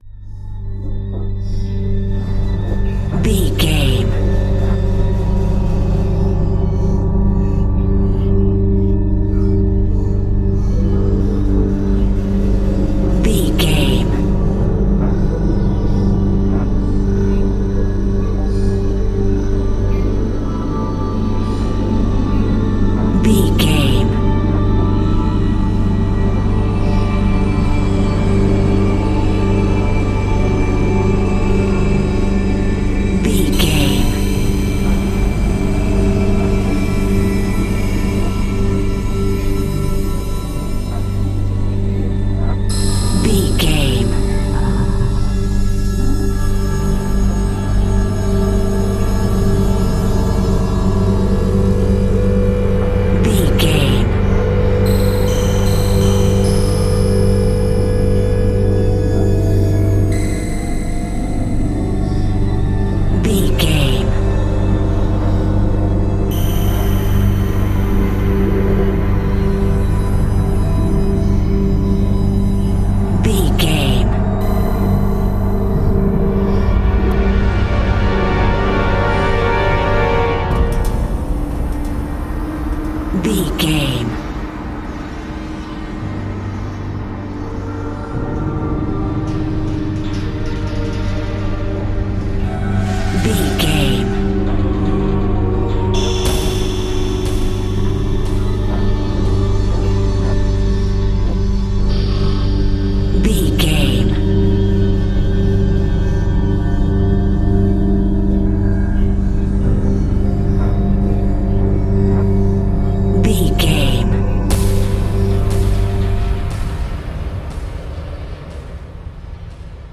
Aeolian/Minor
synthesiser
ominous
dark
suspense
haunting
tense
creepy